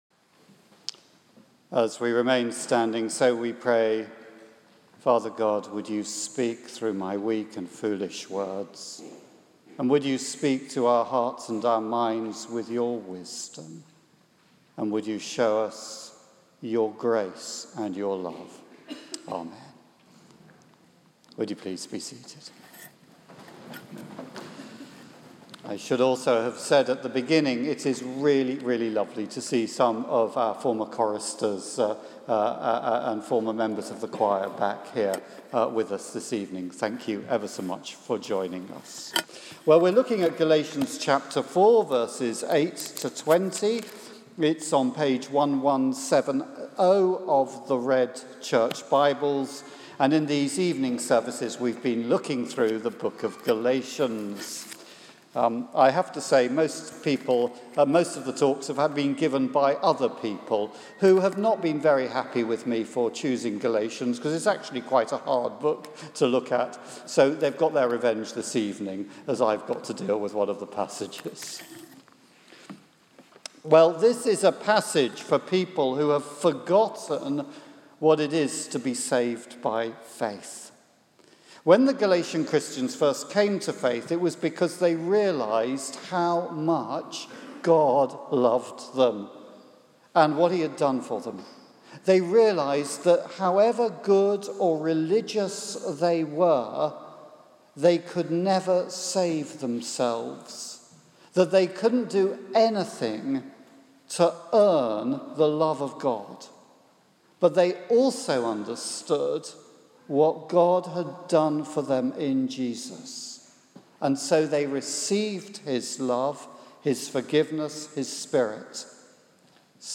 Sermon on Galatians 4.8-20. St Mary's Church.